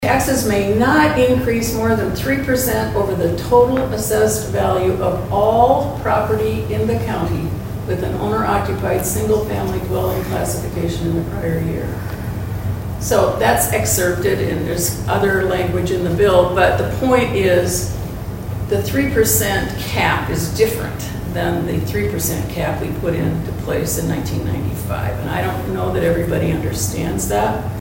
ABERDEEN, S.D.(HubCityRadio)- The Aberdeen Chamber of Commerce’s Chamber Connections Series continue Thursday at the K.O.Lee Public Library.